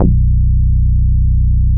CHASE BASS 1.wav